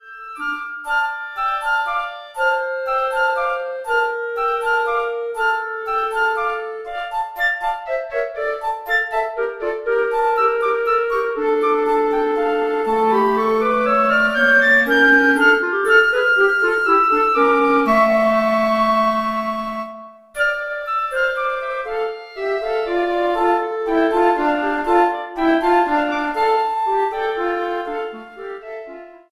Obsazení: 2 Flöten und Klarinette